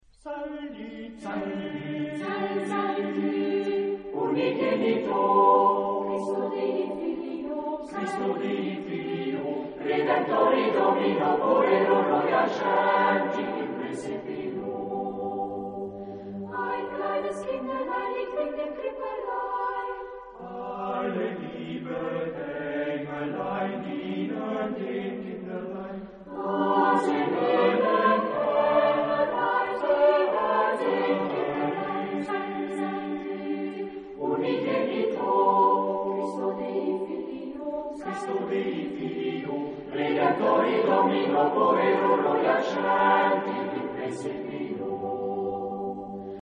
SATB (4 voix mixtes) ; Partition complète.
Renaissance. Baroque. Chant de Noël.